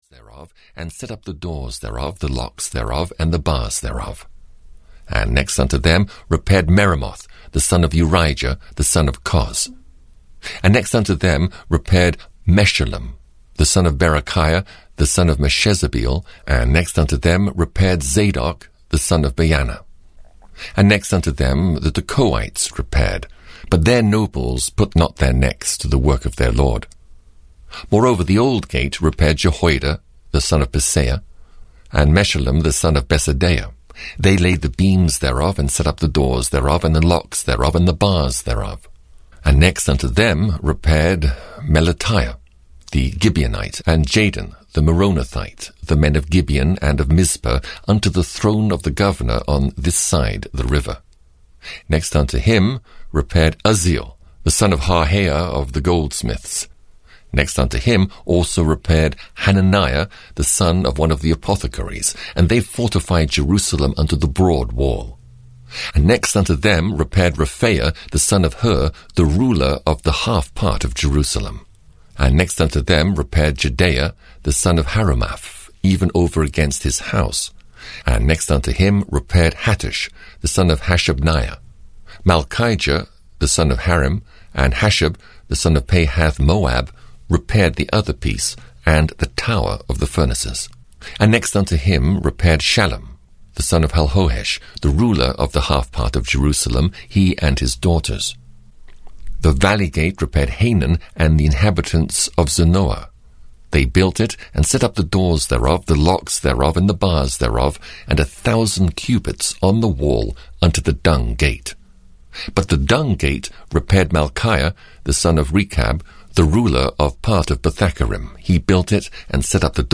The Old Testament 16 - Nehemiah (EN) audiokniha
Ukázka z knihy